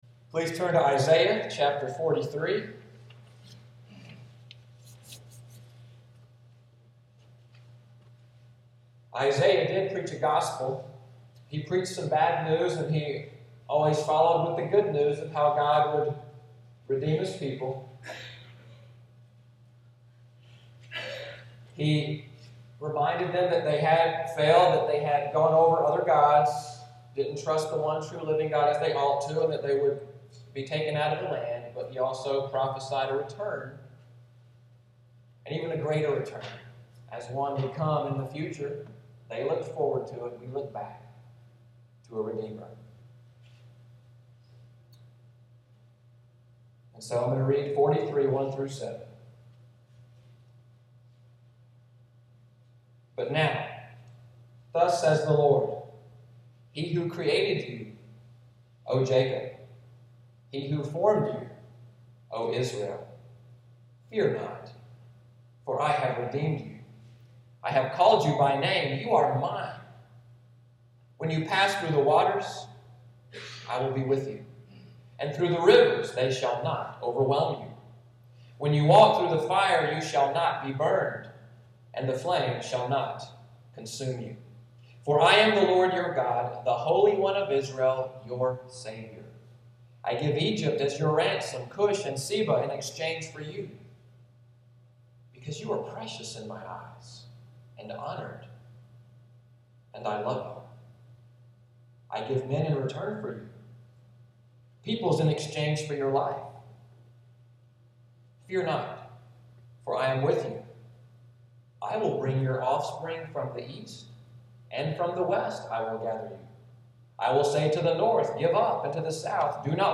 Sunday’s sermon for Sept. 21, 2014: “You Are Mine”